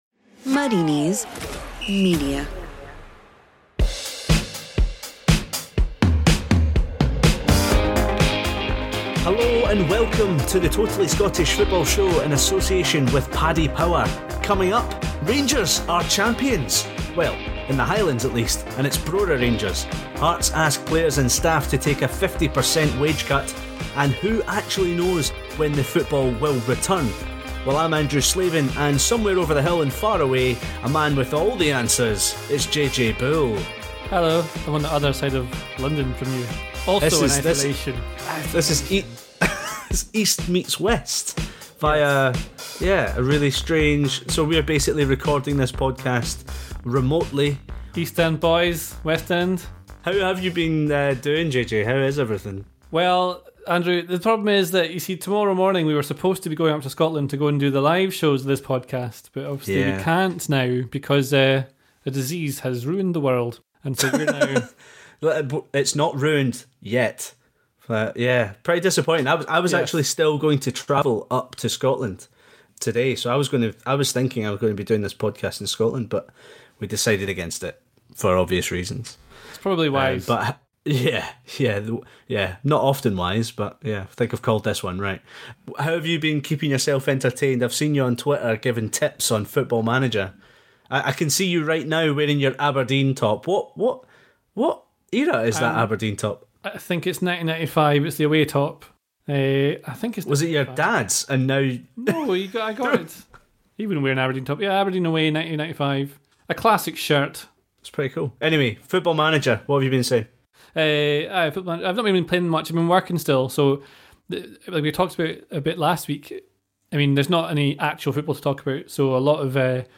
PART 3 - Team of the season with bonus keyboard content